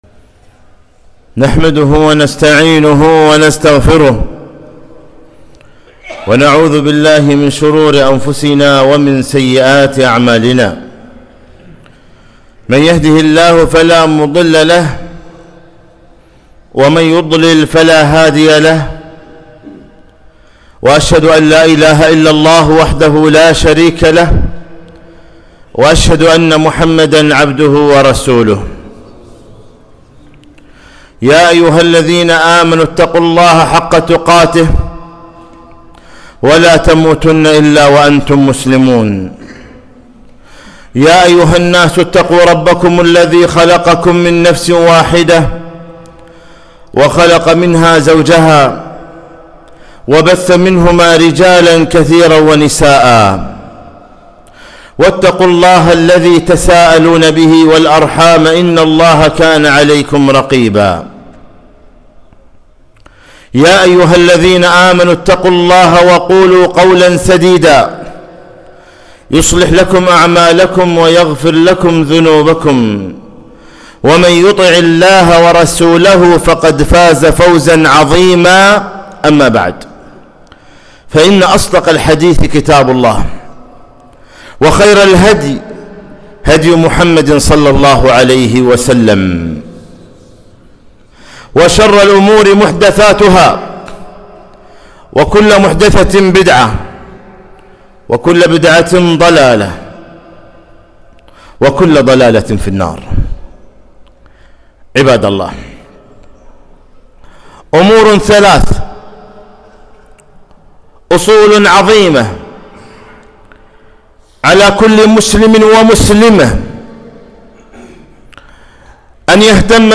خطبة - الأصول الثلاثة التي يُسأل عنها العبد في قبره